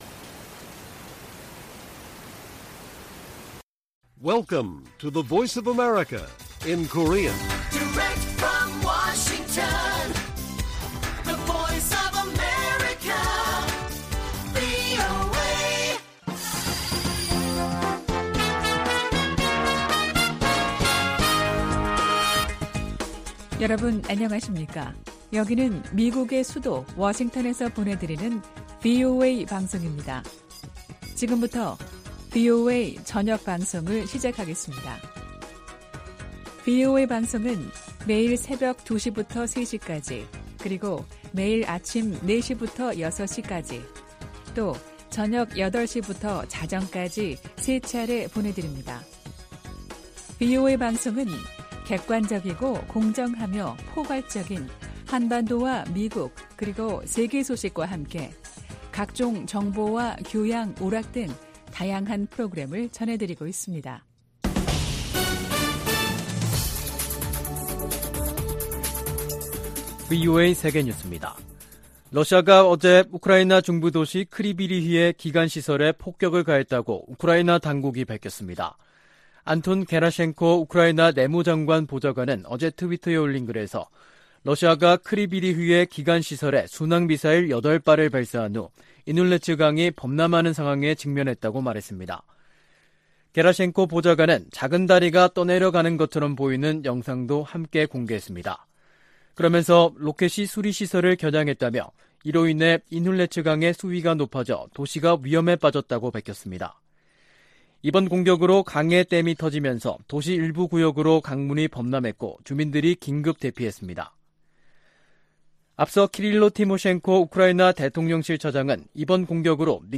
VOA 한국어 간판 뉴스 프로그램 '뉴스 투데이', 2022년 9월 15일 1부 방송입니다. 미 국방부는 북한의 핵무력 정책 법제화와 관련해 동맹의 안전 보장을 위해 모든 조치를 취할 것이라고 밝혔습니다. 미 국제기구대표부는 북한의 핵무력 법제화에 우려를 표명하며 북한은 결코 핵무기 보유국 지위를 얻을 수 없을 것이라고 강조했습니다. 미 상원의원들이 대북 압박과 억지를 유지하고 대북특별대표직을 신설할 것 등을 요구하는 법안을 발의했습니다.